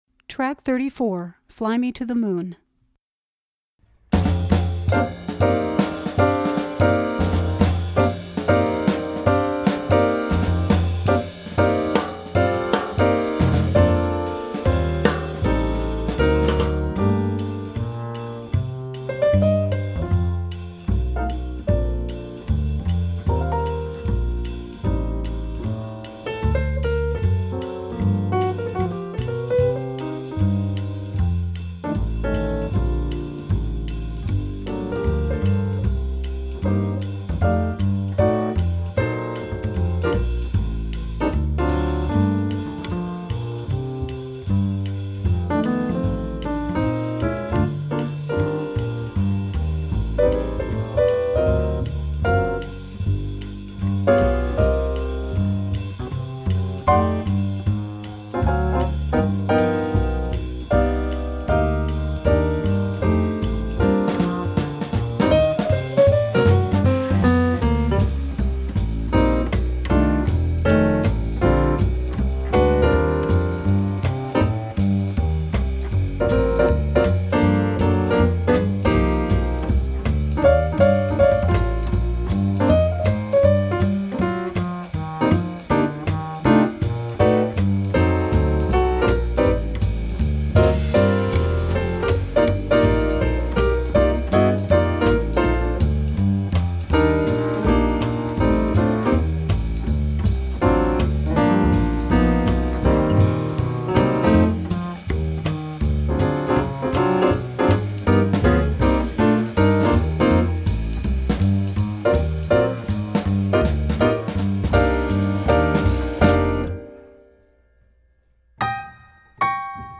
Voicing: Vocal